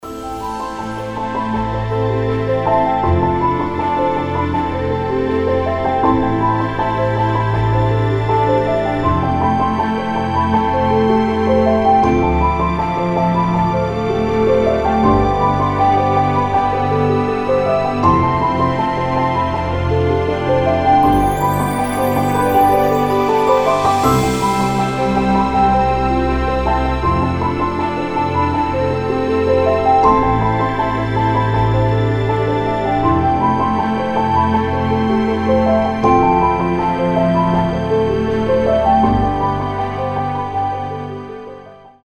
красивые
мелодичные
без слов
New Age
холодные